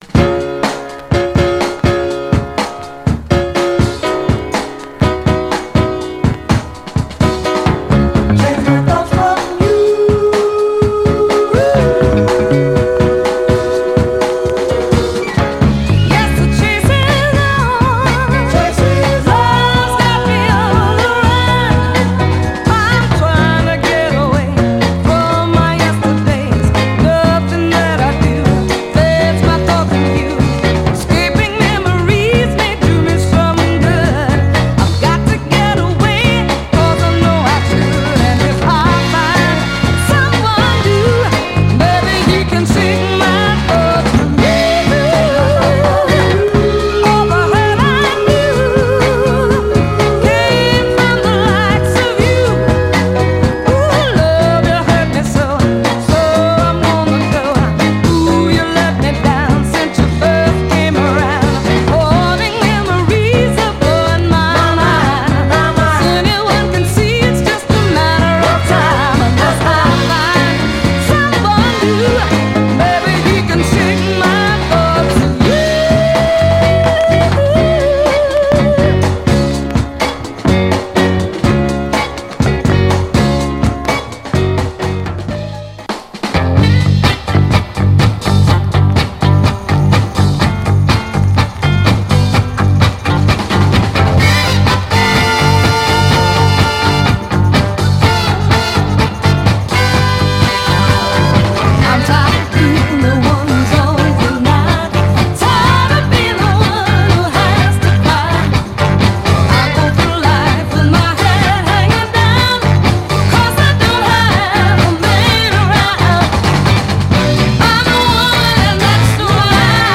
盤は全体的にスレ、指で感じられる目立つ小キズが点々とあり、両面序盤やB面中盤等のキズ箇所でプチッと音に出ます。
※試聴音源は実際にお送りする商品から録音したものです※